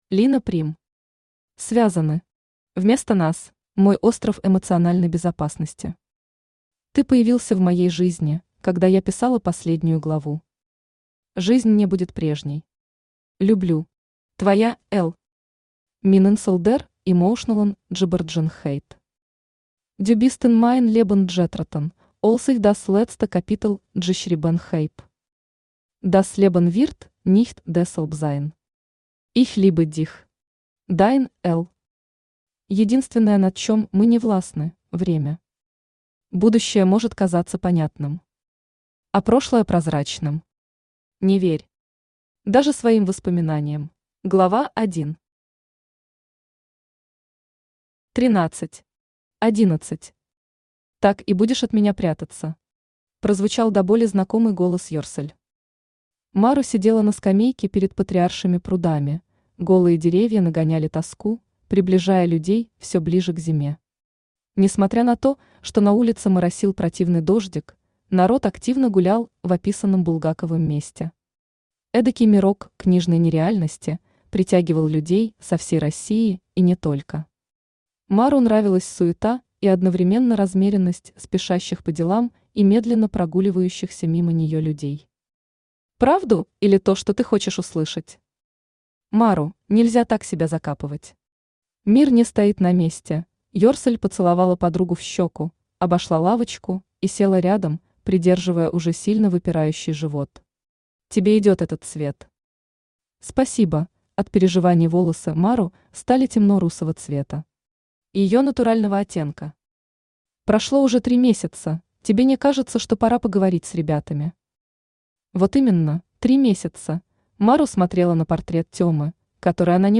Аудиокнига Связаны. Вместо нас | Библиотека аудиокниг
Aудиокнига Связаны. Вместо нас Автор Лина Прим Читает аудиокнигу Авточтец ЛитРес.